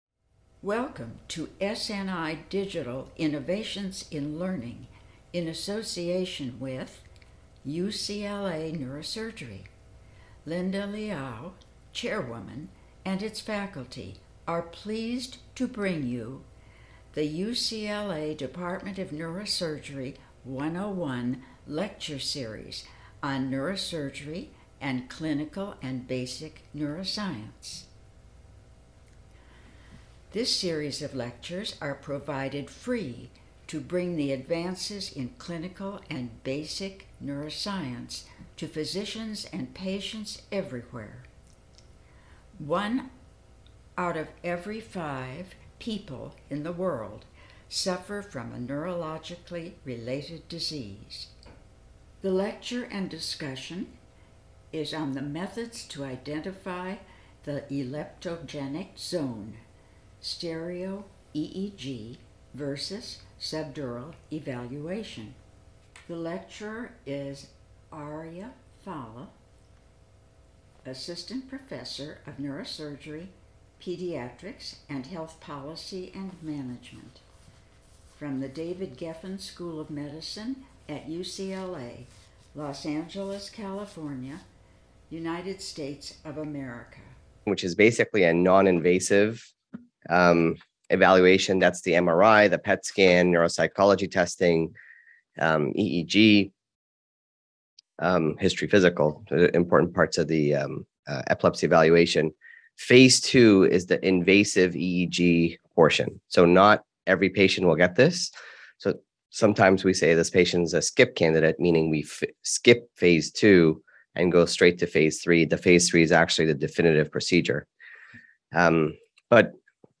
UCLA 101 Neurosurgery Lecture Series